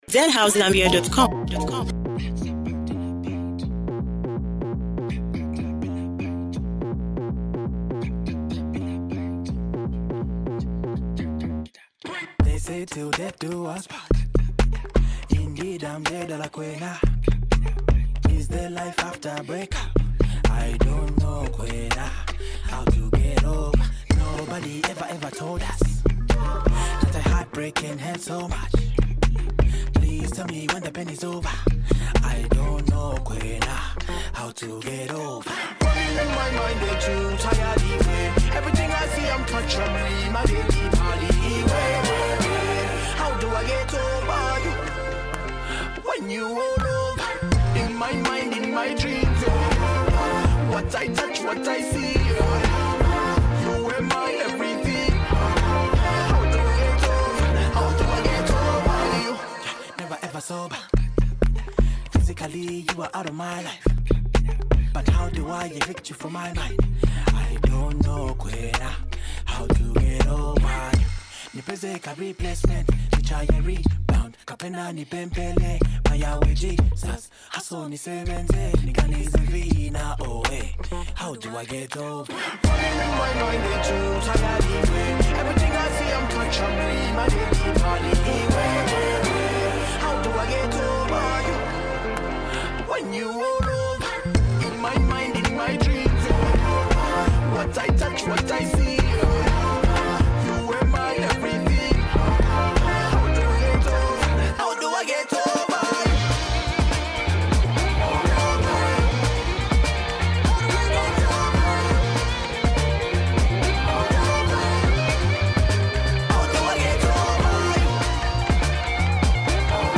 ” delivering a heartfelt anthem of healing and moving on.